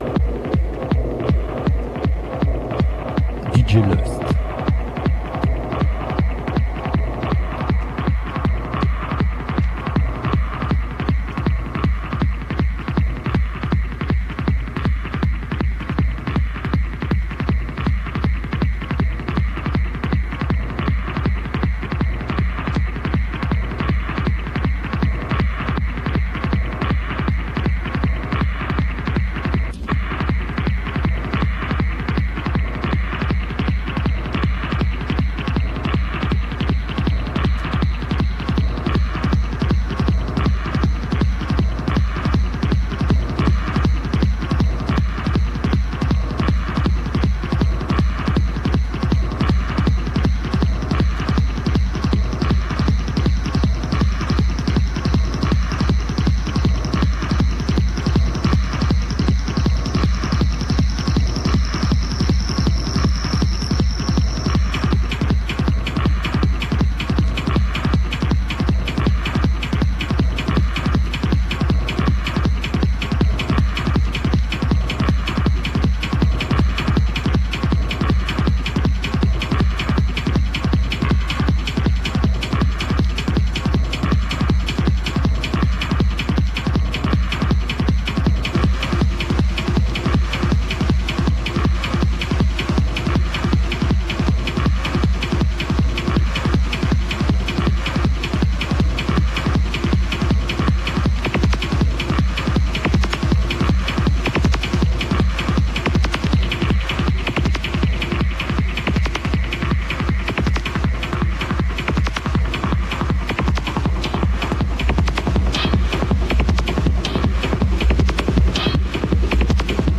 Presque hardcore spatial sur la fin...